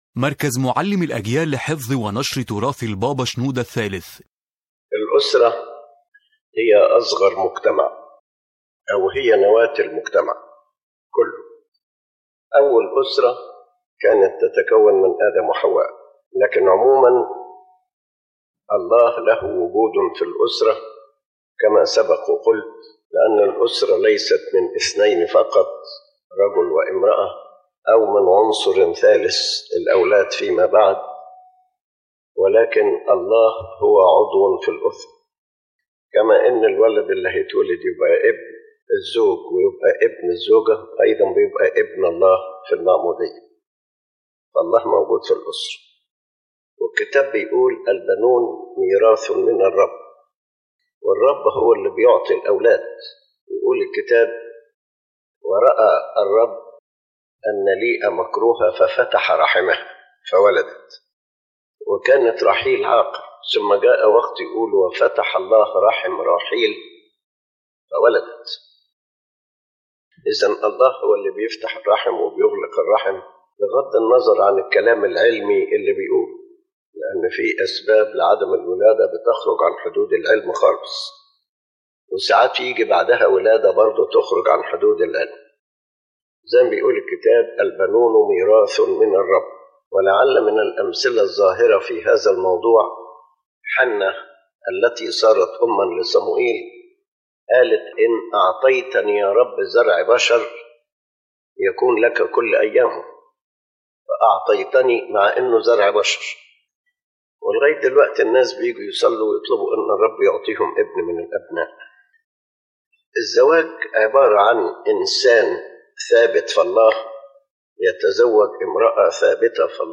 ⬇ تحميل المحاضرة أولًا: الأسرة حضور إلهي وليس مجرد رابطة اجتماعية يؤكد قداسة البابا شنوده الثالث أن الأسرة هي نواة المجتمع، ولكنها ليست مجرد اتحاد بين رجل وامرأة، بل هي كيان يحضر فيه الله.